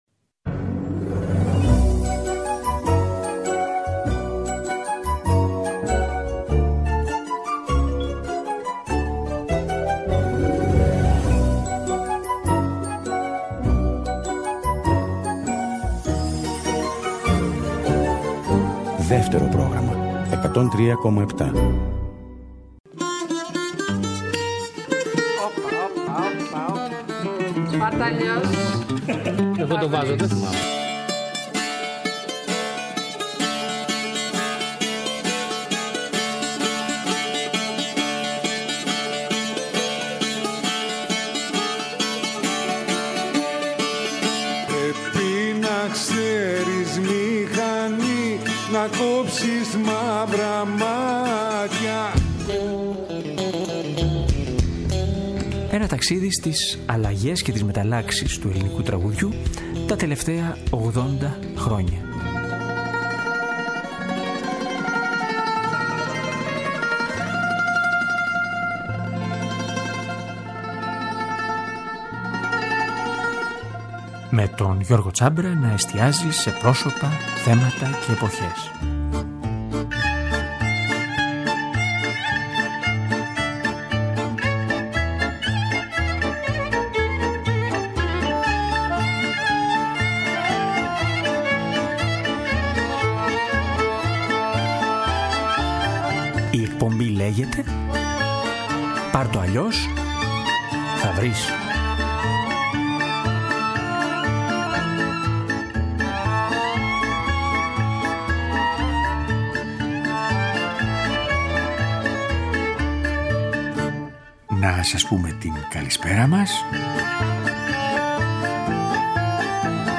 Ρόζα, Νίνου και Χασκίλ : Καθώς φωνές γυναικών του σήμερα συναντούν «δικές μας ξένες» από τα παλιά